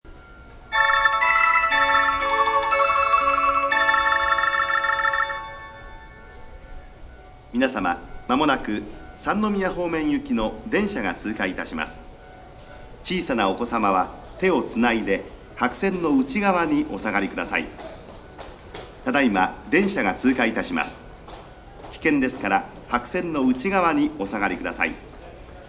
tsukaguchi_1pass.mp3